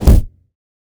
Massive Punch B.wav